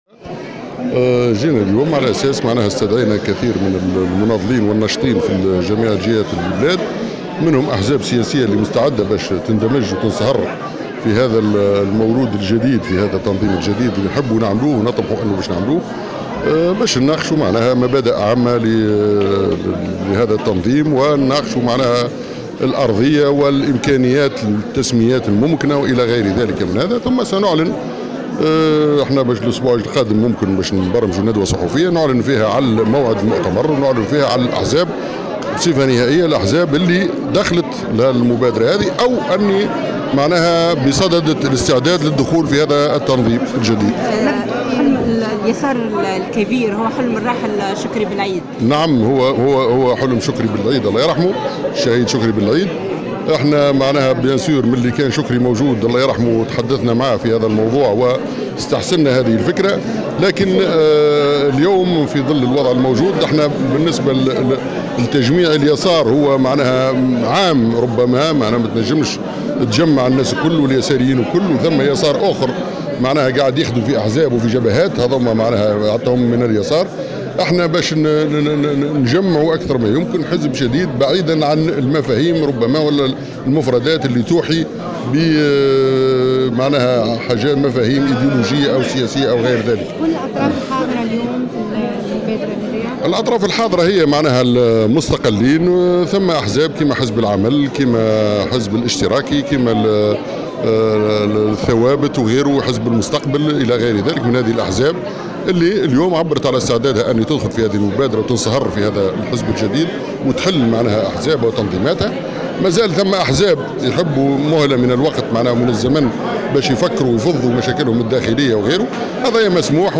واكد عدنان الحاجي في تصريح صحفي ان هذه المبادرة تضم مستقلين وعددا من الأحزاب من الحزب الاشتراكي والعمل والمستقبل والثوابت وغيرهم من الاحزاب، موضحا أن هذه المبادرة غير معنية بالانتخابات البلدية. وكشف أن الجبهة الشعبية لم تحضر هذا الاجتماع وأنها لا ترحب بفكرة هذه المبادرة معتبرا ان الجبهة ليست الخيار الوحيد لليسار.